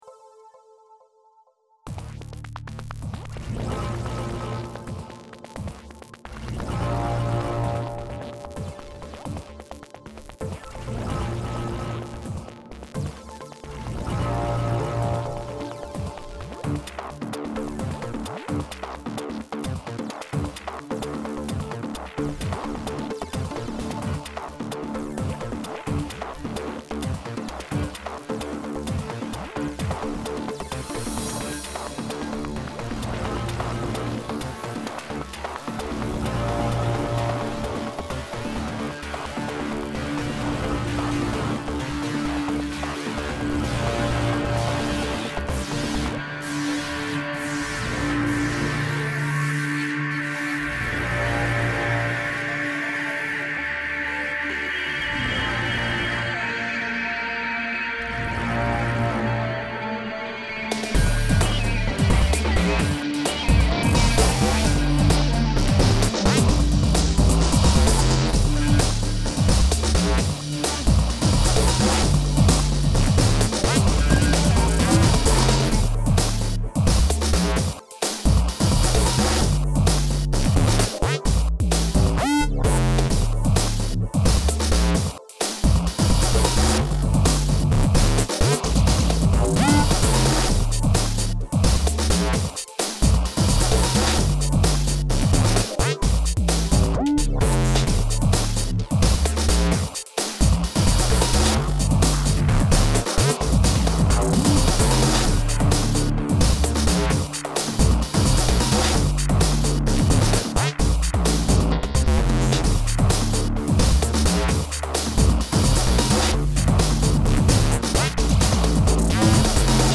dance/electronic
Breaks & beats
Trance